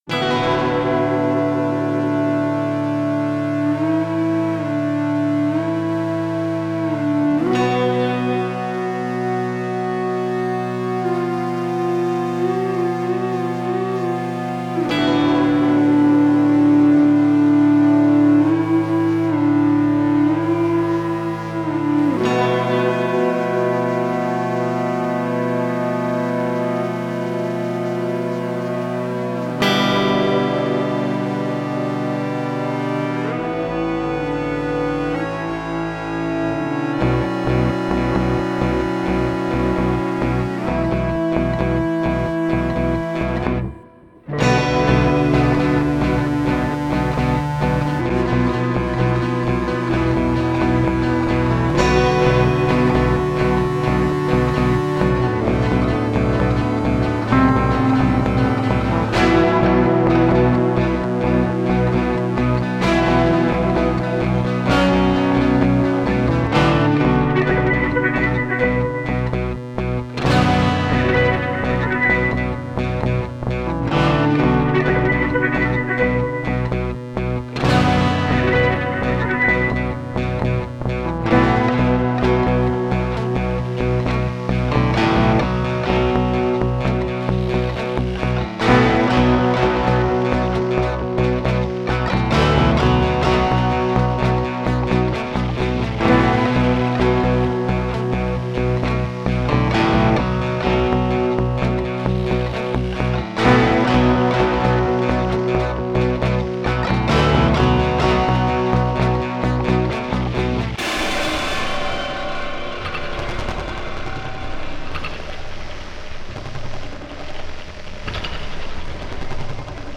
100 guitares sur un bateau ivre
4/4, tempo = 65 puis 130.
C'est la version studio du 20 avril.
[0'00] thème/mélodie d'intro, [0'44] la suite avec l'entrée des deux parties rythmique, [1'50] fade out marin [2'19].